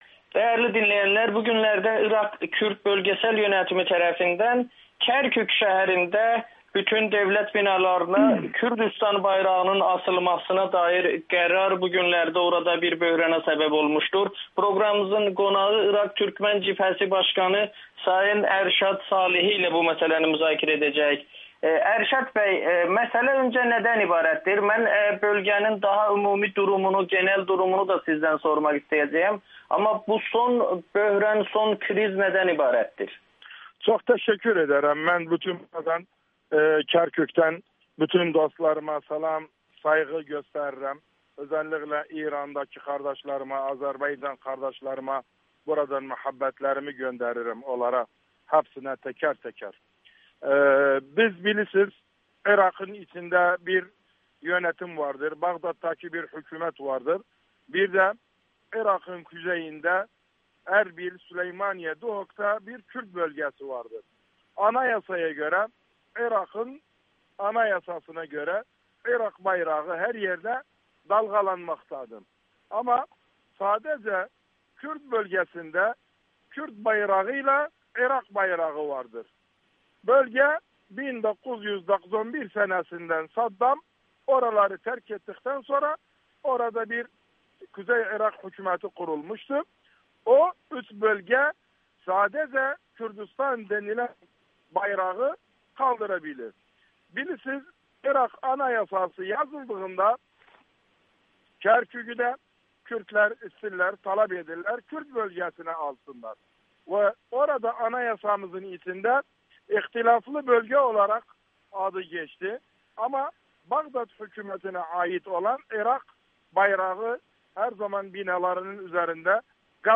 Amerikanın Səsinə müsahibədə o, bu günlərdə Kərkükdə yaşanan bayraq gərginliyi ilə bağlı son durumu şərh edib.